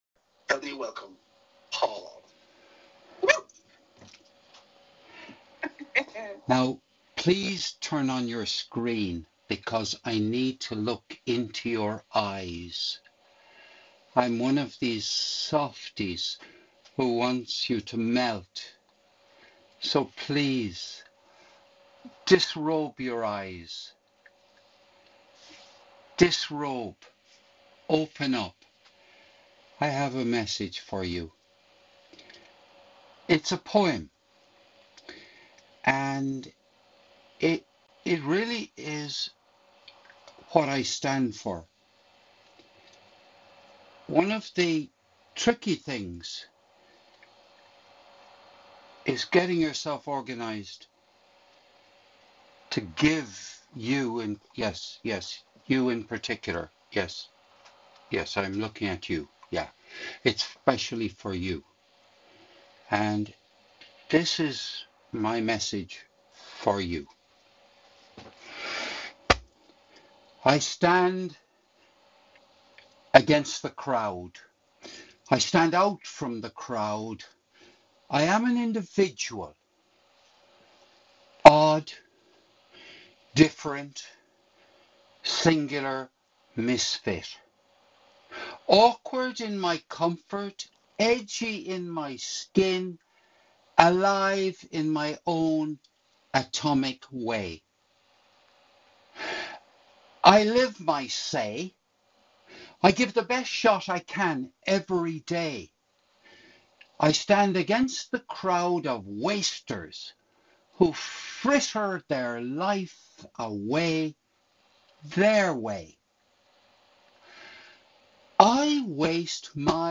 This was recorded live at a meeting of Leading Voices Toastmasters Club in Nassau, the Bahamas - on Sunday 23rd February 2025.